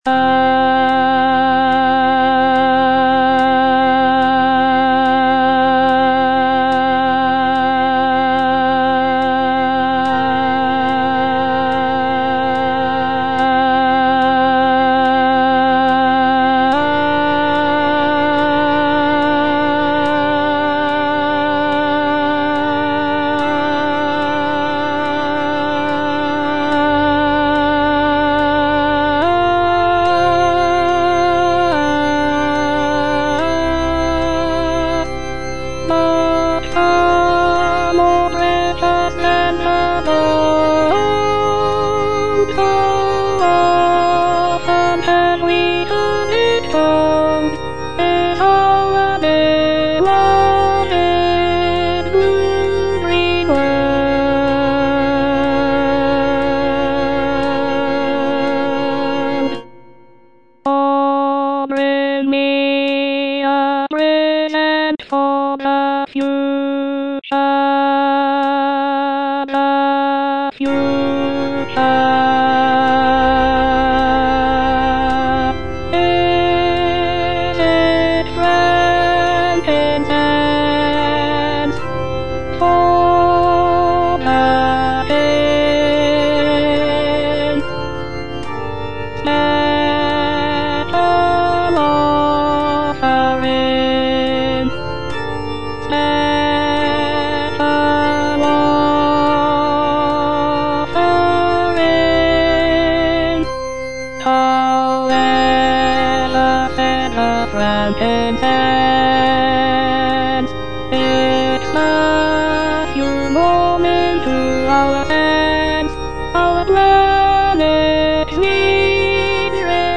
Alto II (Voice with metronome)
choral work